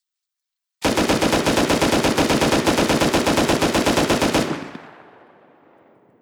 LR 300 Assault Rifle Sound Effect Free Download
LR 300 Assault Rifle